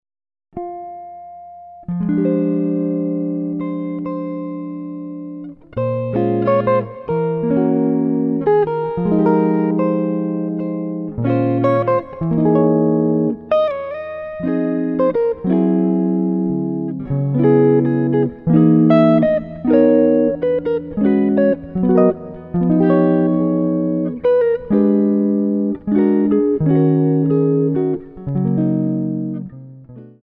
solo electric guitar